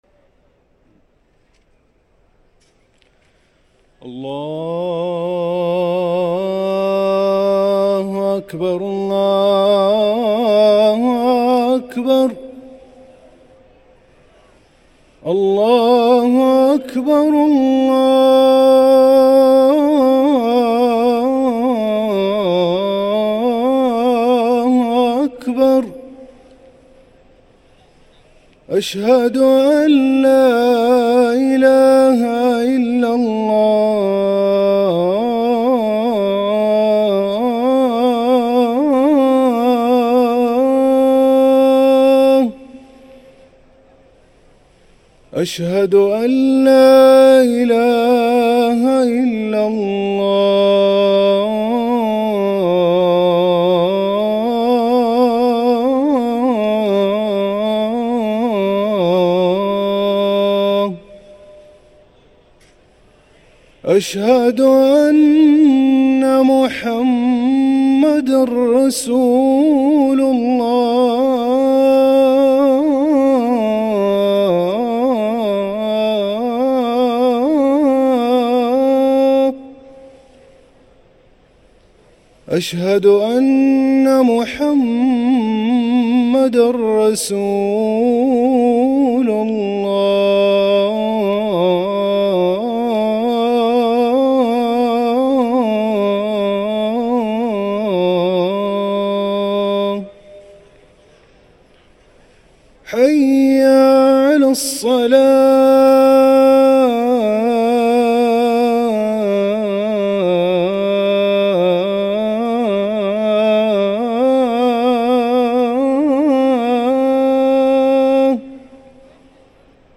أذان العصر للمؤذن هاشم السقاف الأحد 11 صفر 1445هـ > ١٤٤٥ 🕋 > ركن الأذان 🕋 > المزيد - تلاوات الحرمين